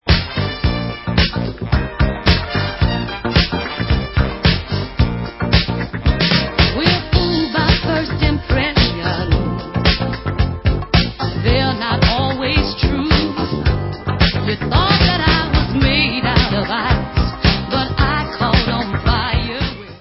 Dance/Soul